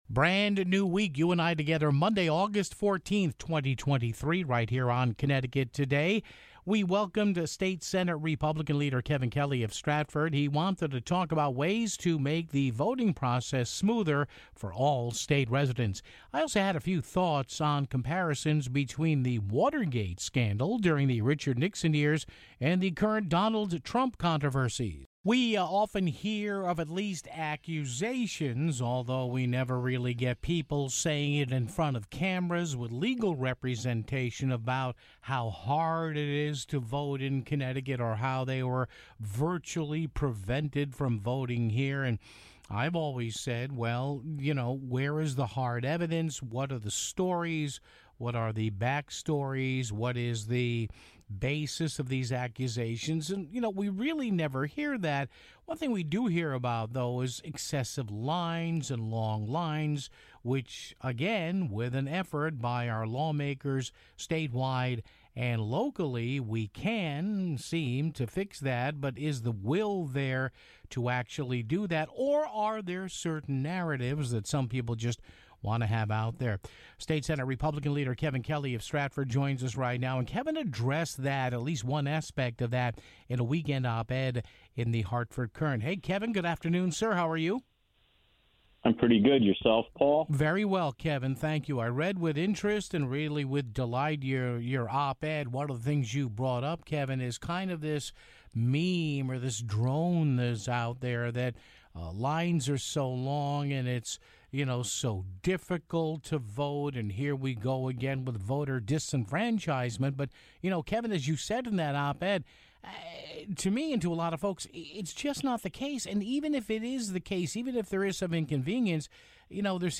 featured State Senate GOP leader Kevin Kelly of Stratford on ways to make the voting process smoother for state residents (0:25).